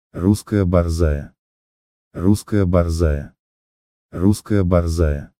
BORZOI - Russkaya Borzaya, Русская борзая